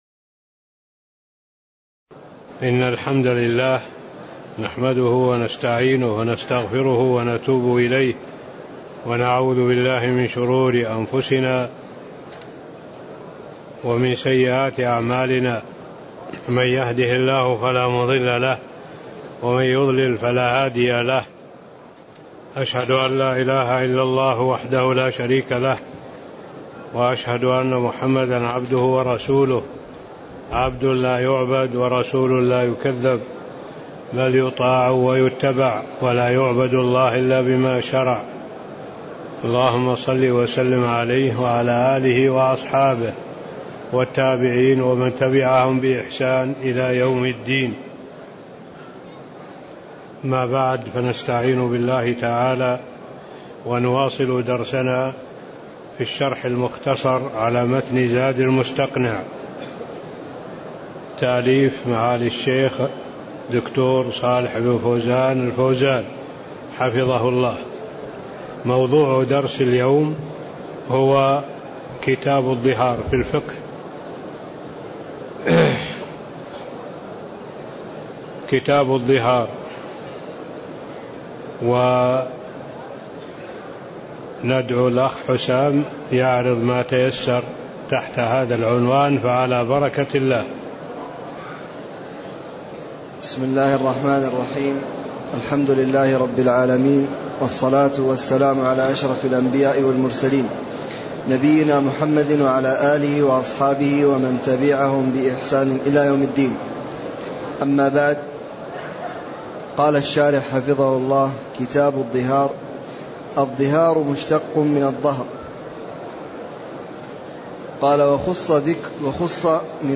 تاريخ النشر ٢٧ رجب ١٤٣٥ هـ المكان: المسجد النبوي الشيخ: معالي الشيخ الدكتور صالح بن عبد الله العبود معالي الشيخ الدكتور صالح بن عبد الله العبود قوله: والظهار مشّتق من الظهر (01)